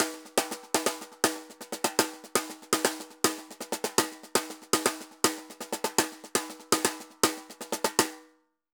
Tambor_Merengue 120_1.wav